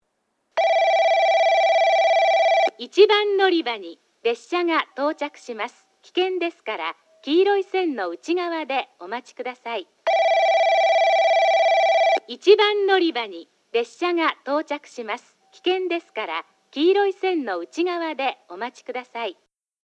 1番のりば　接近放送　女声
スピーカーはJVCラインアレイとTOAラッパ型、UNI‐PEXラッパ型です。